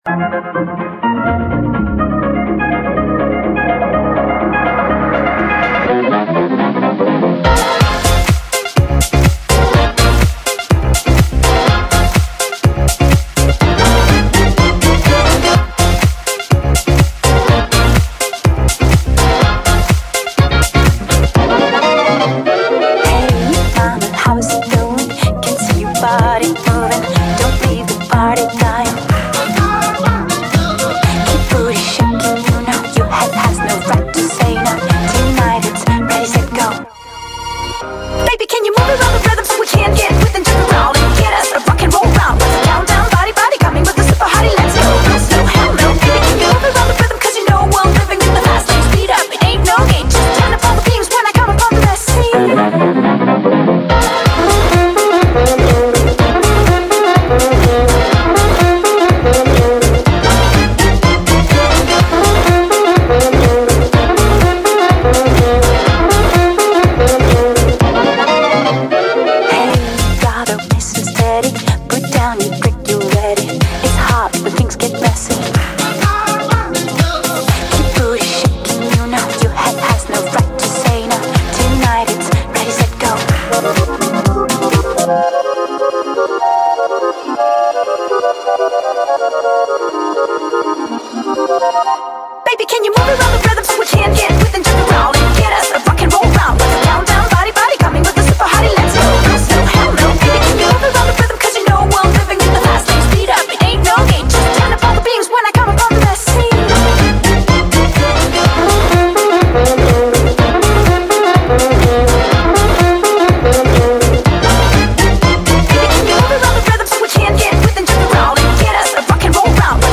BPM124
MP3 QualityMusic Cut